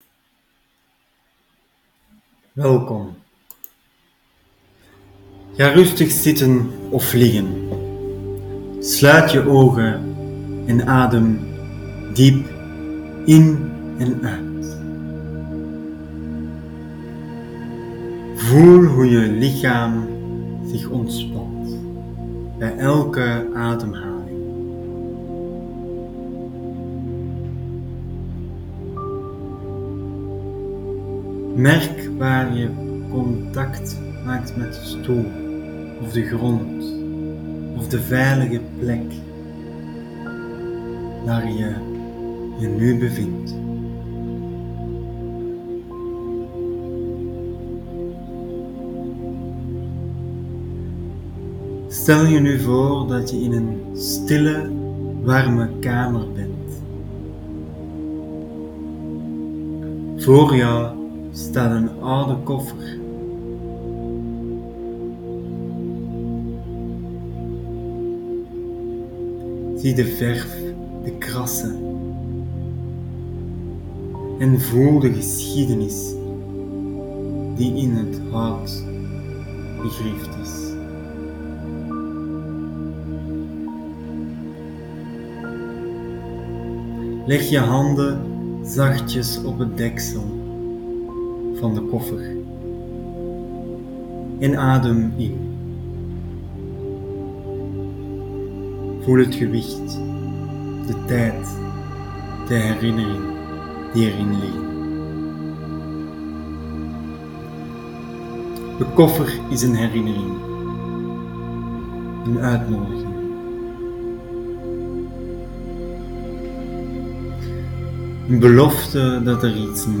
Deze meditatie is een cadeautje.
Meditatie 1 Het openen van de koffer.m4a